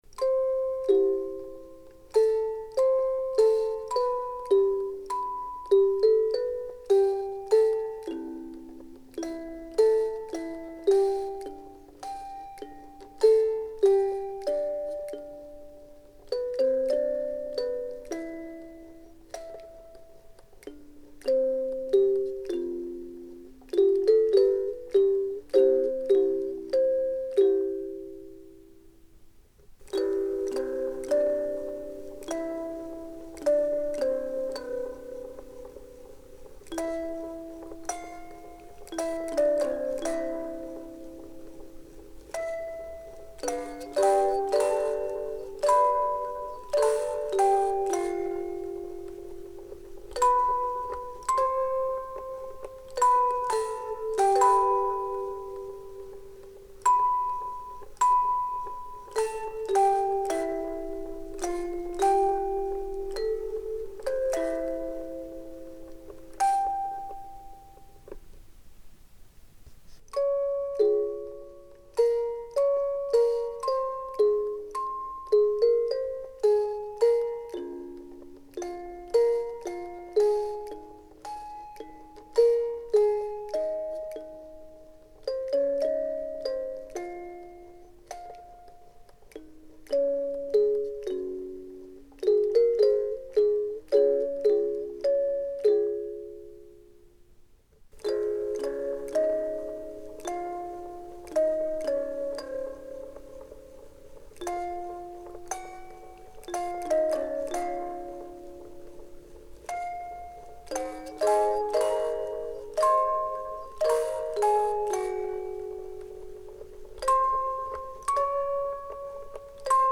ビデオとパフォーマンスのサウンドトラックとしてつくったものです。
オルゴール